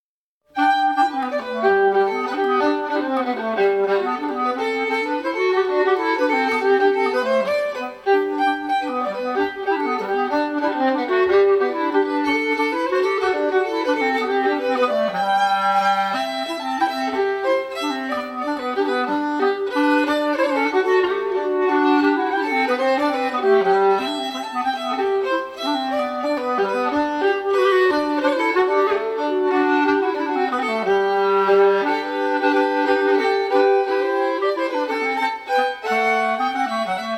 Vals
Inspelad: Studio 41 - 2001